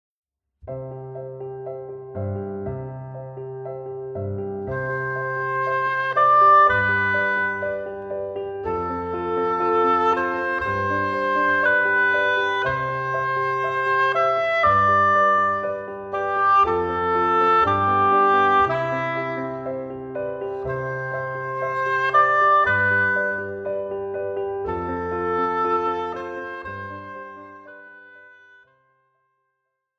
14 Vorspielstücke für Oboe mit Klavierbegleitung
Besetzung: Oboe und Klavier